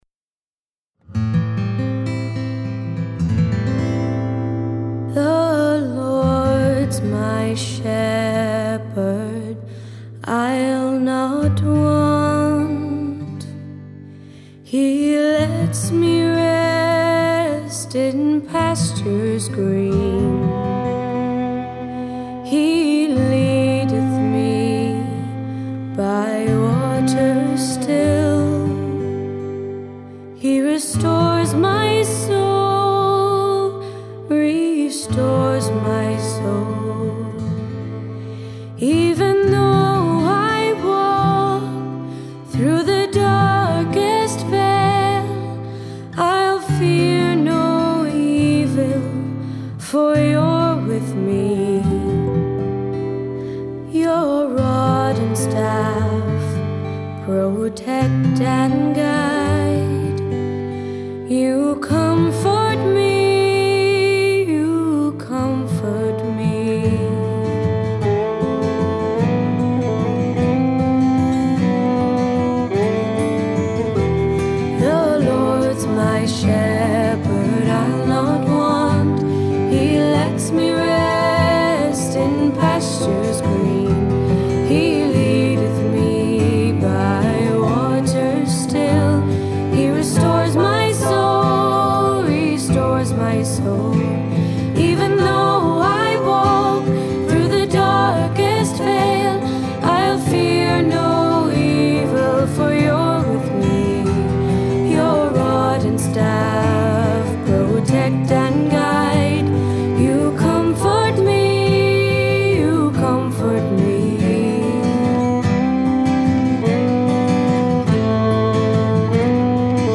Voicing: Solo; Assembly